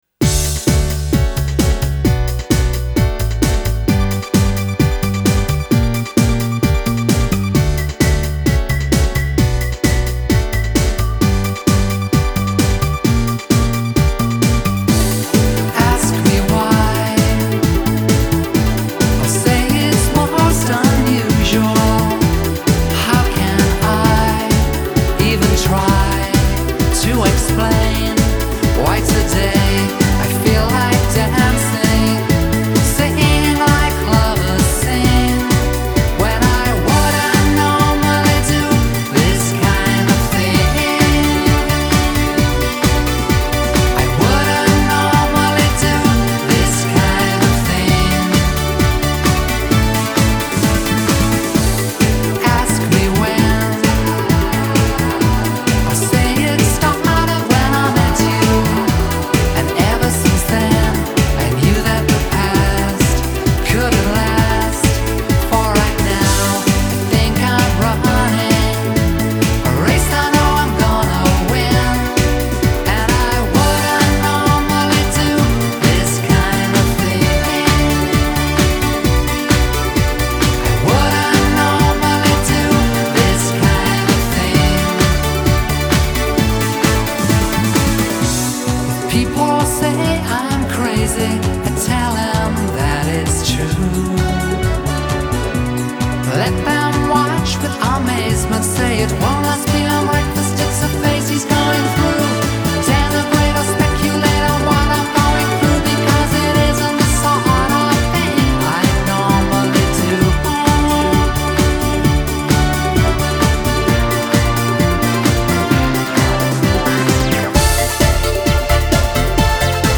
Жанр: Electronic, Pop, House, Synth pop